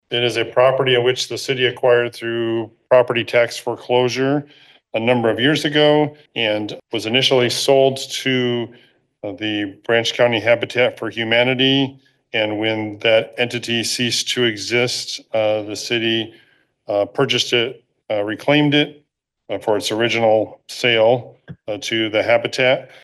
A resolution and agreement to buy the property was introduced during Monday night’s Coldwater City Council meeting.